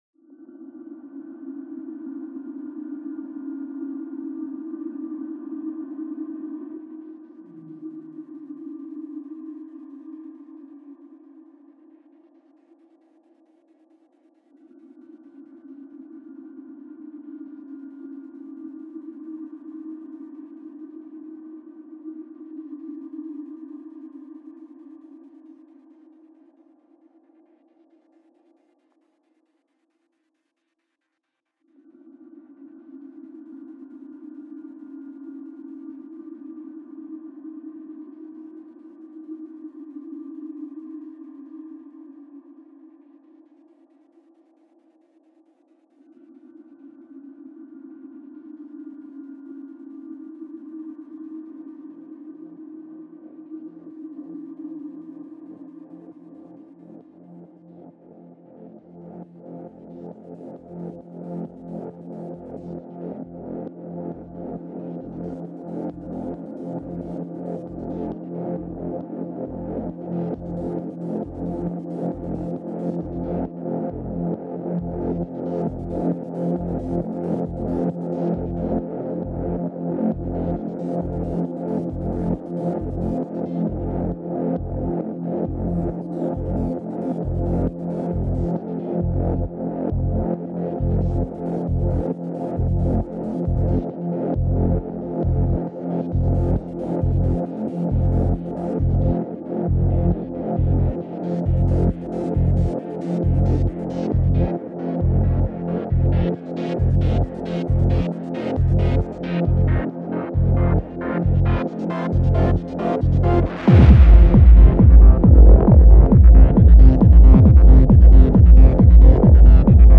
special live set